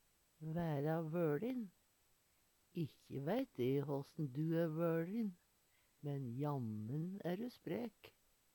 DIALEKTORD PÅ NORMERT NORSK væra vøLin være laga Eksempel på bruk Ikkje veit e håssen du æ vøLin, men jammen æ du spræk. Høyr på uttala Ordklasse: Uttrykk Kategori: Kropp, helse, slekt (mennesket) Uttrykk Attende til søk